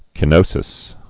(kĭ-nōsĭs)